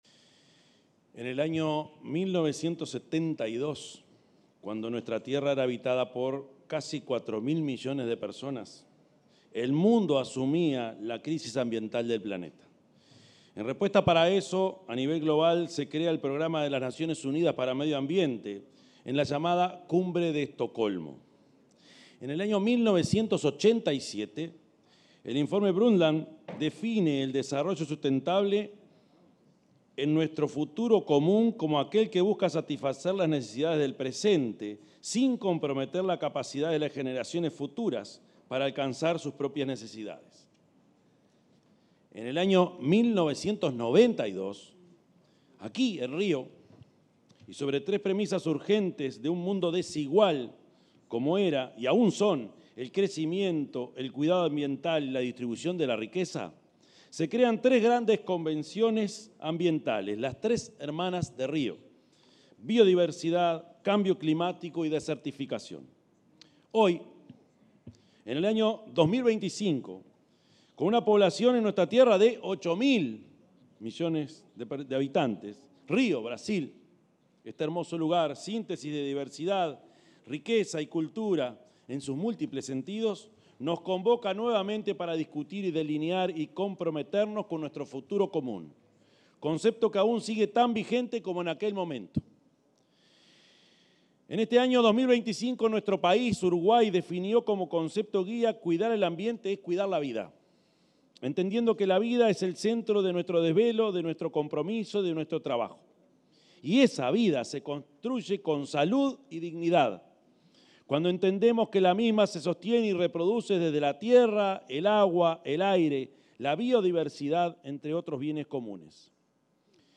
El presidente de la República, profesor Yamandú Orsi, disertó durante la última sesión de la Cumbre de los BRICS, en Río de Janeiro, Brasil.